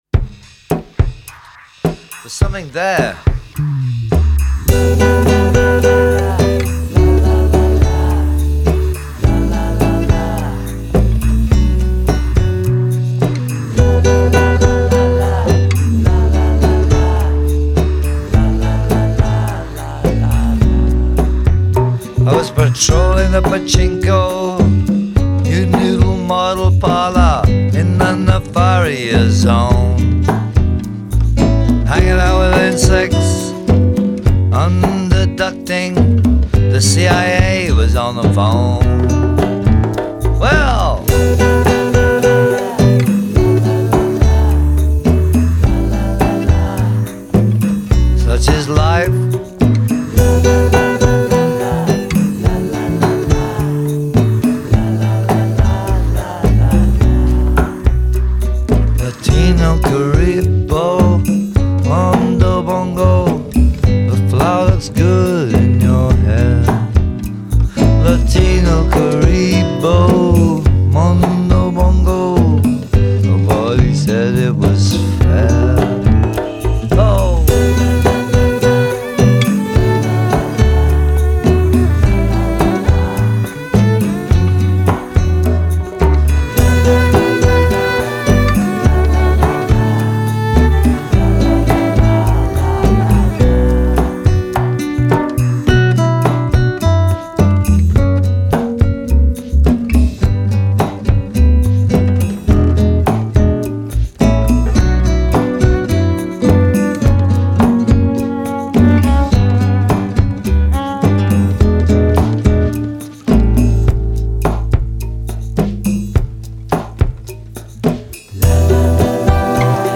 带出中南美洲自在摇摆的拉丁曲式